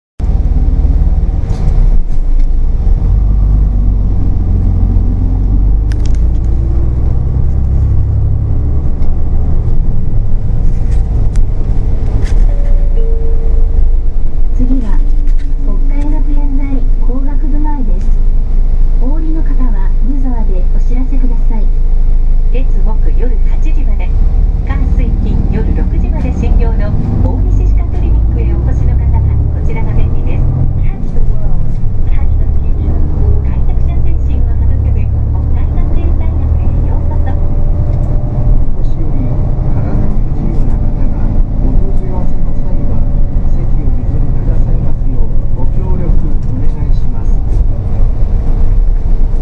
車内放送(最新)     ドアが閉まった際に「発車致します、ご注意下さい」と流れます。